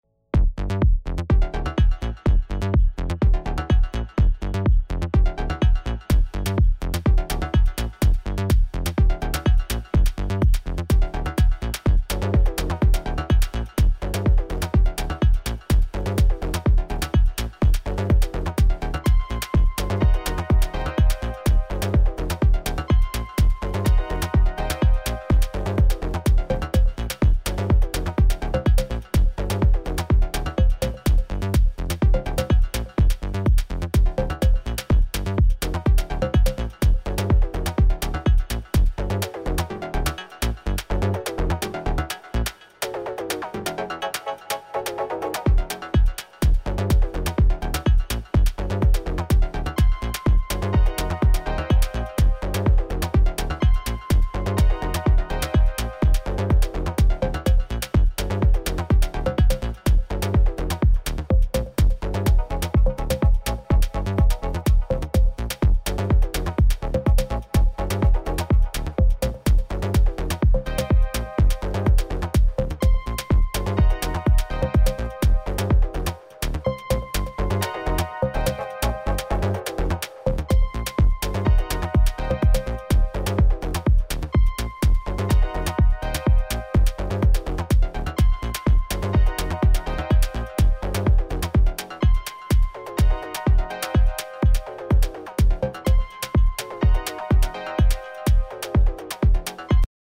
I have to listen from the monitors then I will tell you .hehe Now listening from in ear headphones and yours sounds really nice the low ends kicks heavy like 808 . Nice contrast with the highs .